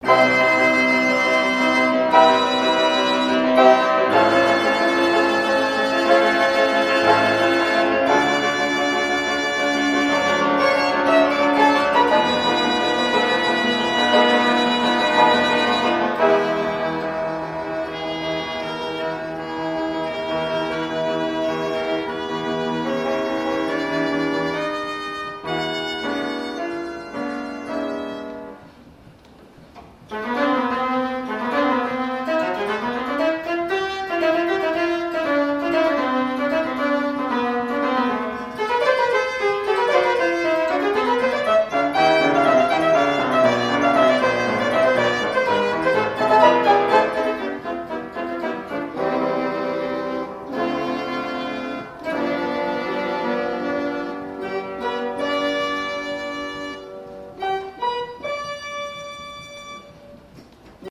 PHILLIPS PAGANINI MODEL 3 GEIGEN-PIANO